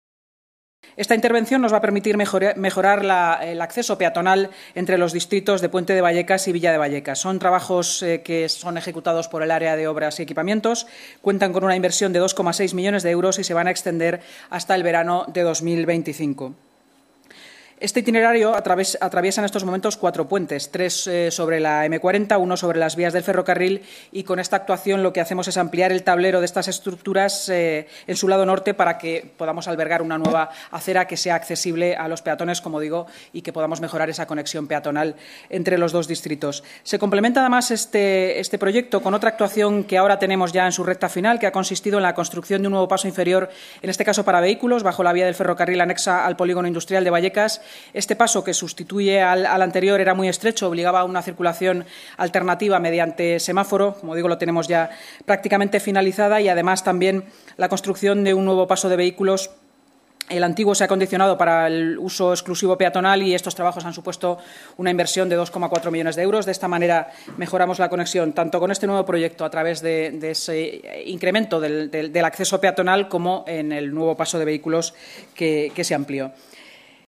Nueva ventana:La vicealcaldesa de Madrid y portavoz municipal, Inma Sanz: sobre la mejora de accesibilidad en el Pozo del Tio Raimundo